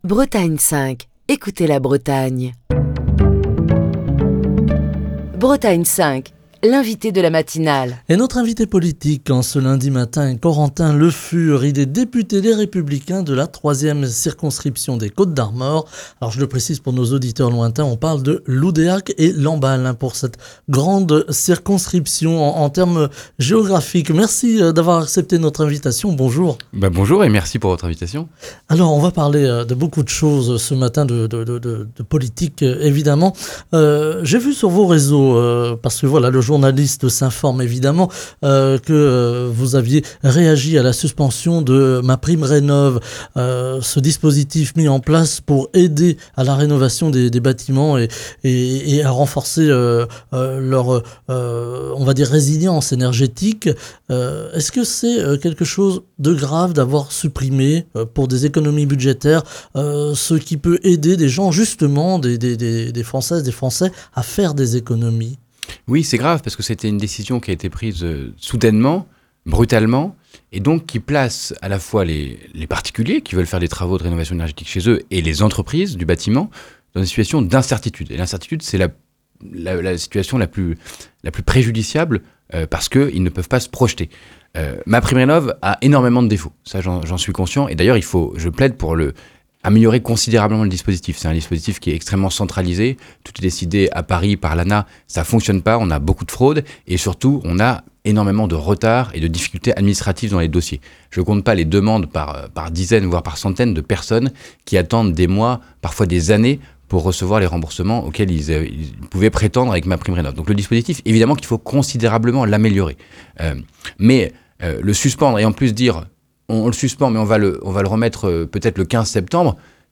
Corentin Le Fur, député Les Républicains de la 3e circonscription des Côtes d’Armor (Lamballe - Loudéac), était l'invité de la matinale de Bretagne 5, ce lundi.